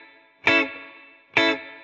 DD_TeleChop_130-Amaj.wav